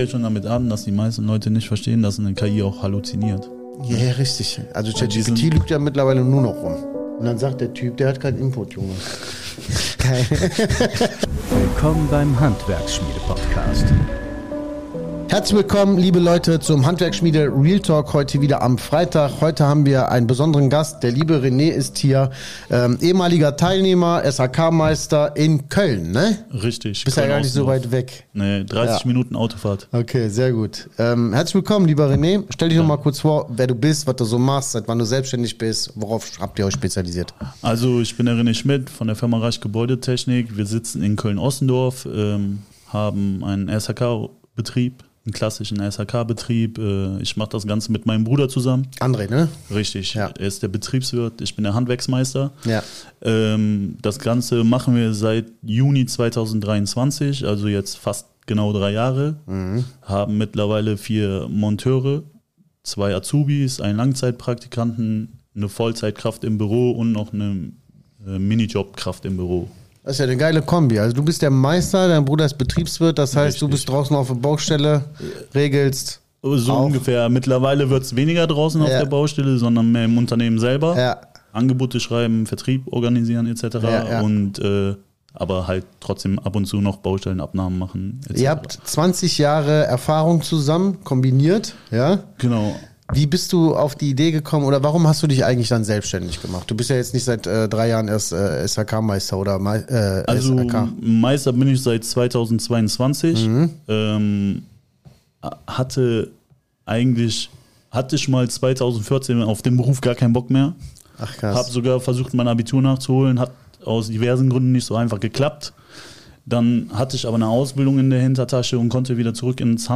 Konkurrenz-Kampf im Handwerk beginnt: Viele nutzen KI falsch | Interview